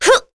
Seria-Vox_Attack1.wav